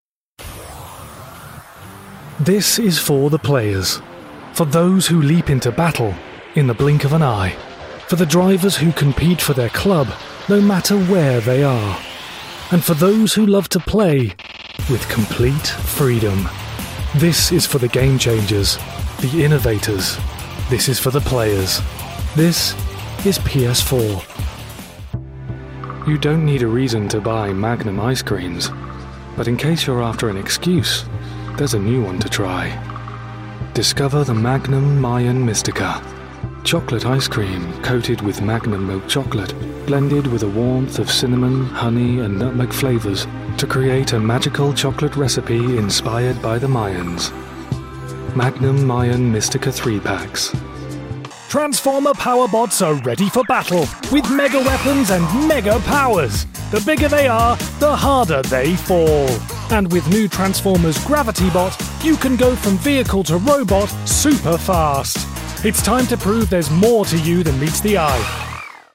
An engaging and warm British voice.
Commercial Reel 2024
Middle Aged